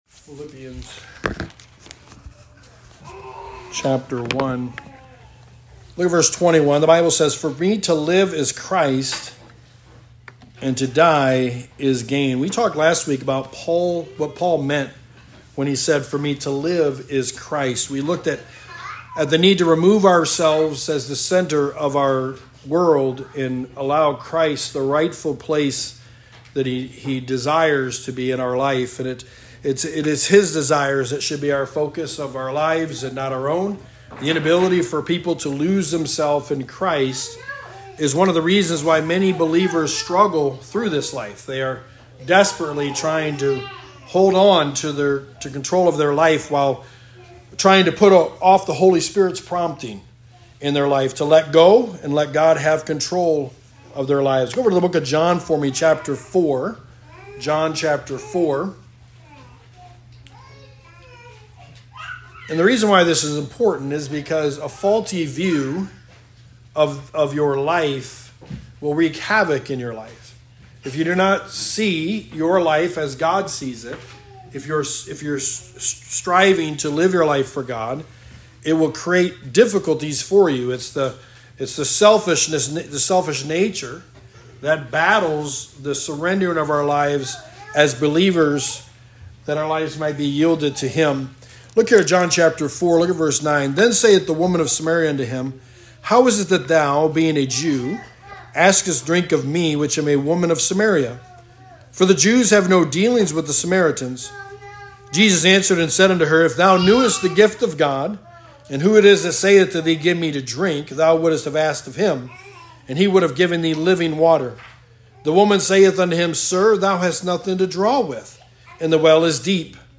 Sermon 8: The Book of Philippians: To Die Is Gain
Service Type: Sunday Morning